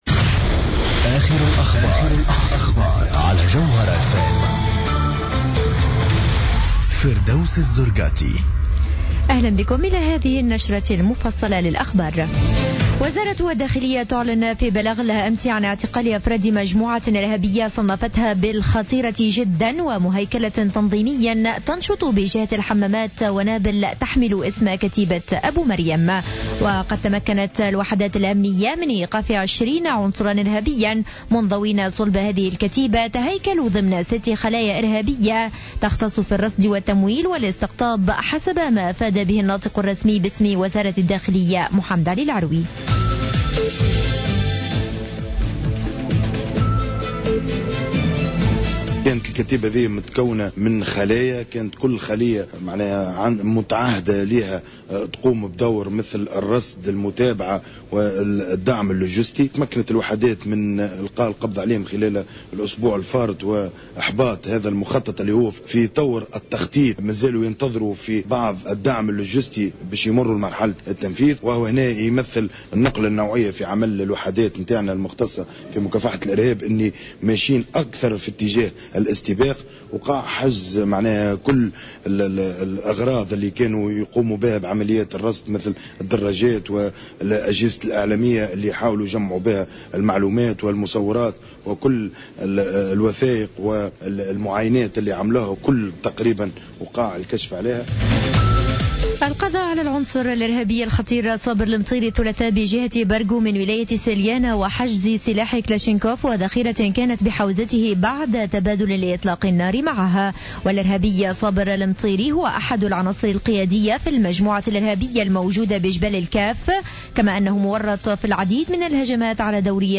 نشرة أخبار منتصف الليل ليوم الخميس 12 فيفري 2015